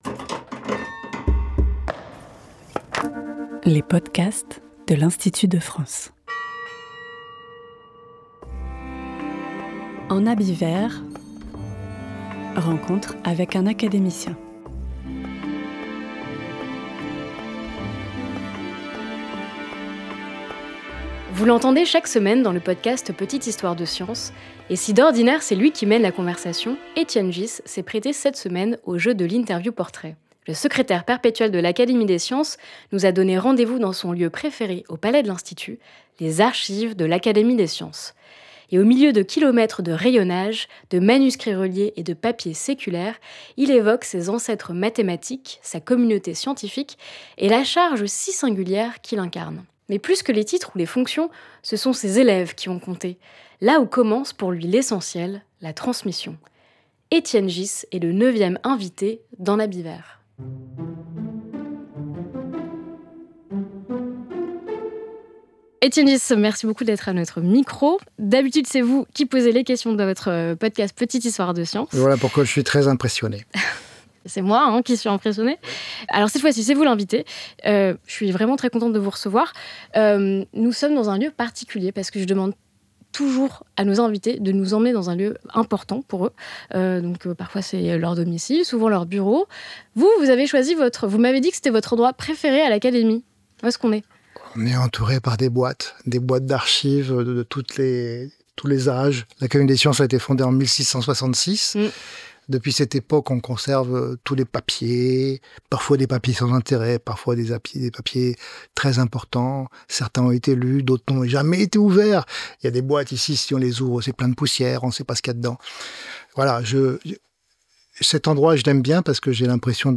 Étienne Ghys a accepté d’inverser les rôles et de passer de l’autre côté du micro pour se prêter à l’exercice du portrait. Le Secrétaire perpétuel de l’Académie des sciences nous a donné rendez-vous dans l’un de ses lieux de prédilection au sein du Palais de l’Institut : les archives de son académie. Au cœur de ce dédale silencieux, entre manuscrits reliés, rayonnages imposants et papiers séculaires, il convoque ses « ancêtres mathématiques », évoque la communauté scientifique qui l’entoure, et revient sur la fonction si singulière qu’il incarne aujourd’hui.